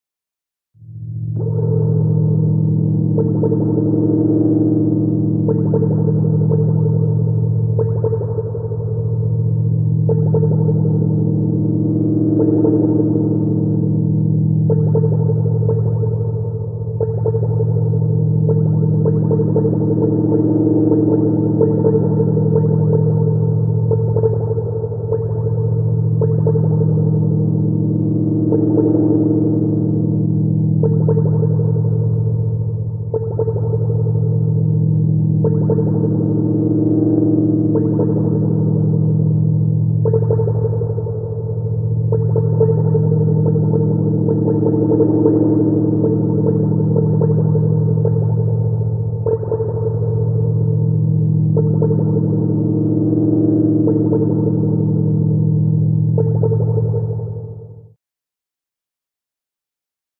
Eerie Atmospheres | Sneak On The Lot
Electronic Atmosphere; Eerie Electronic Atmosphere. Low Note Coming And Going Overlaid With Reverberating 'plip-plips'.